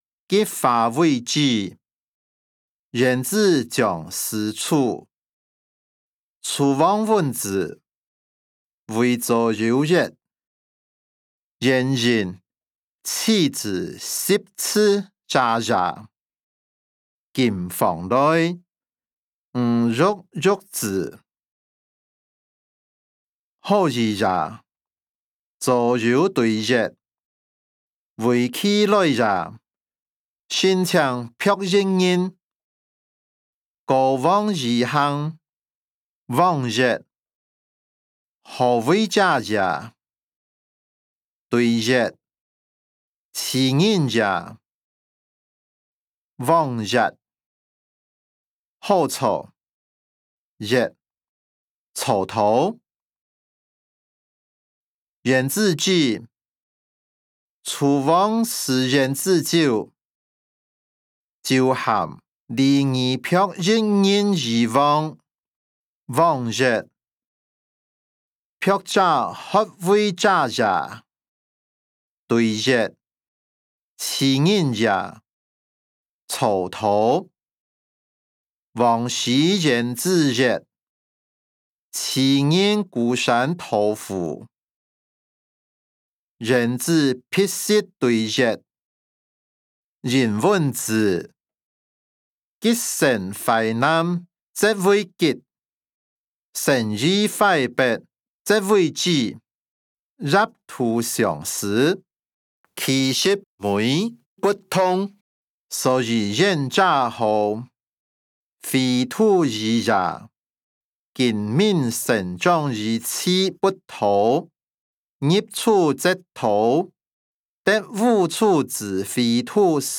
歷代散文-橘化為枳音檔(饒平腔)